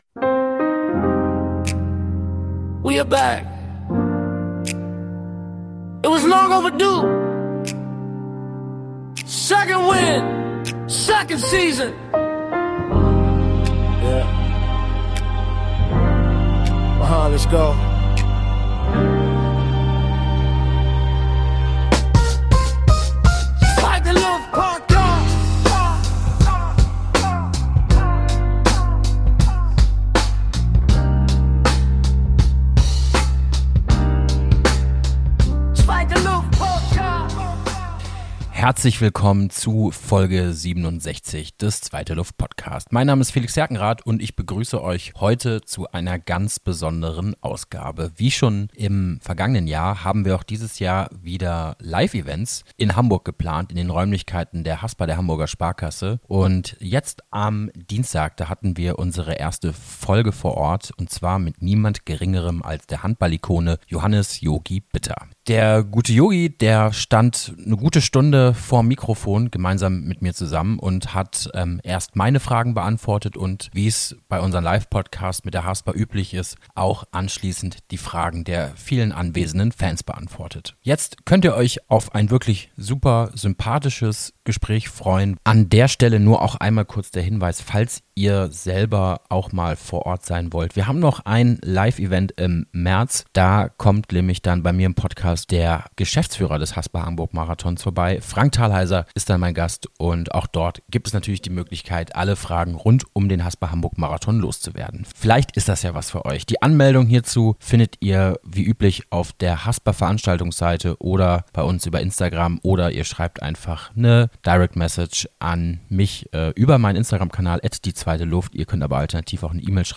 Beschreibung vor 2 Monaten In dieser besonderen Live-Ausgabe begrüße ich die Handballlegende Johannes "Jogi" Bitter auf der Bühne.